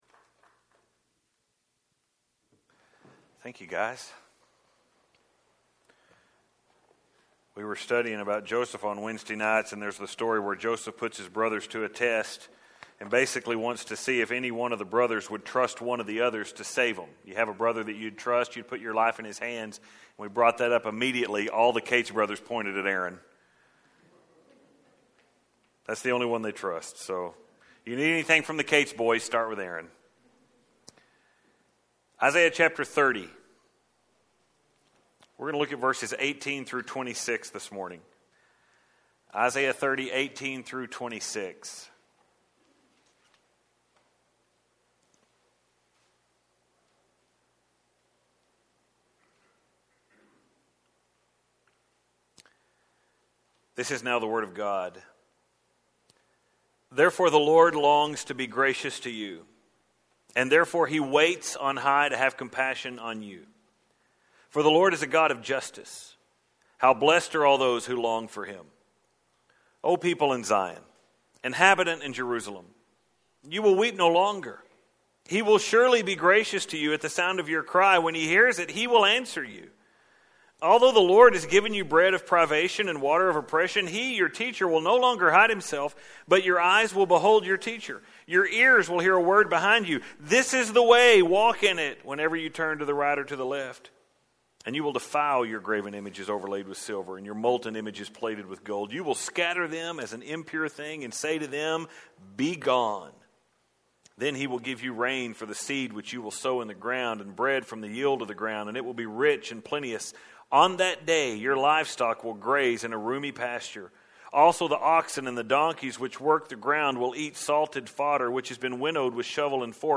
We started this chapter LAST SUNDAY MORNING Where we talked about the reality that many in Jerusalem Were being considered by God as “false sons”.